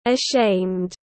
Ashamed /əˈʃeɪmd/